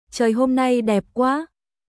チョイ ホム ナイ デップ クア！🔊